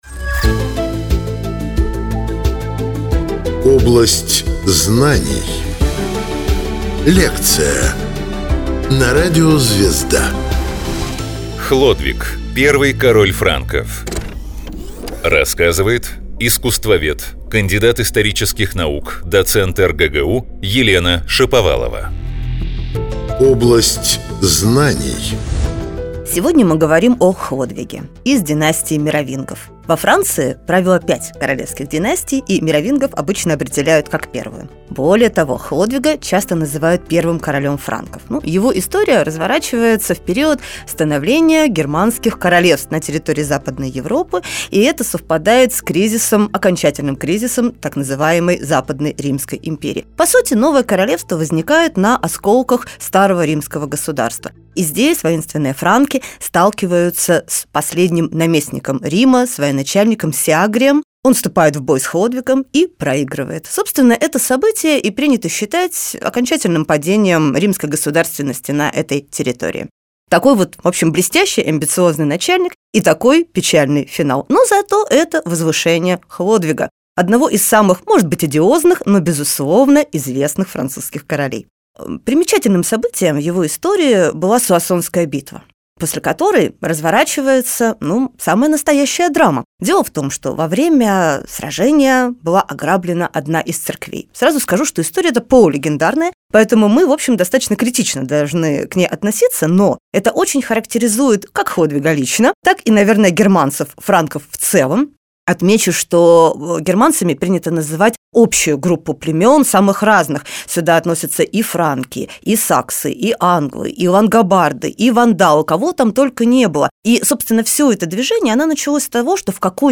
Почему до сих пор не нашли лекарство от рака? Лекция